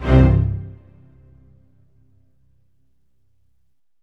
ORCHHIT C2-L.wav